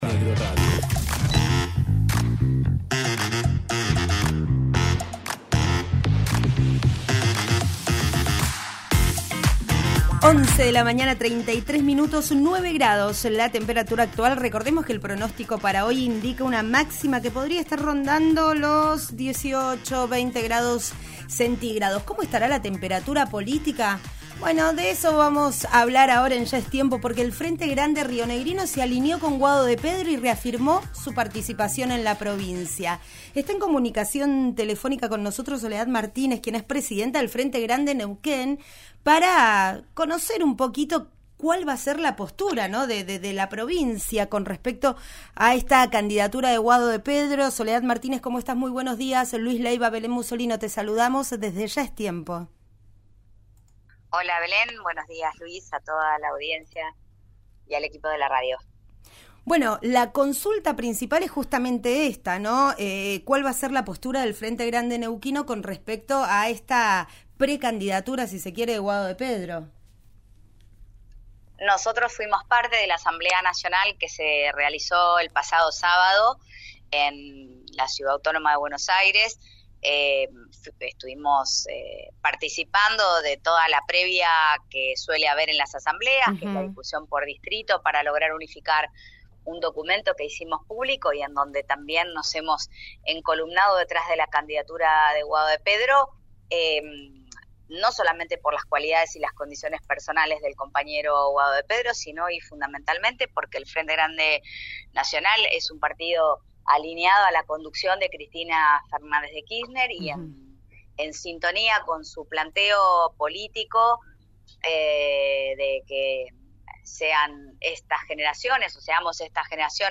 Escuchá a la diputada Soledad Martínez en «Vos Al Aire» por RÍO NEGRO RADIO: